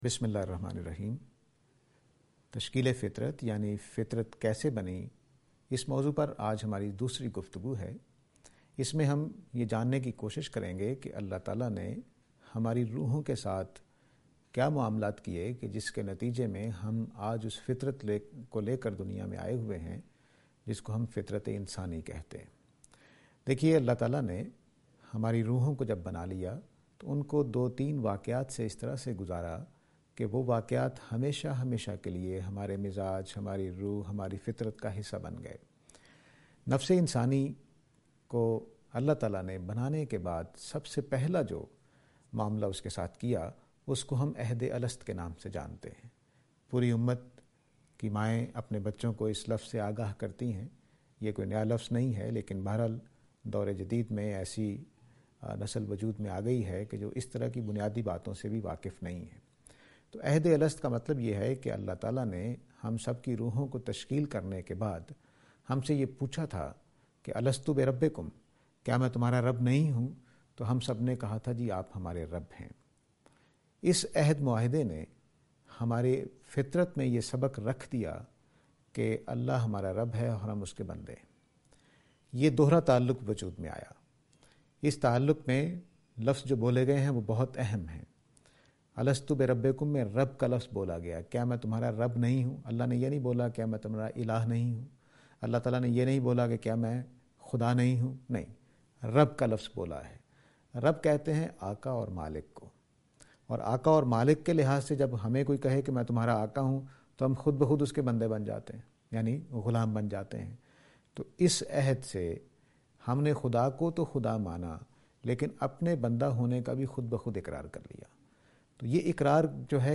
This lecture is and attempt to answer the question "Construction of Nature (Covenant)".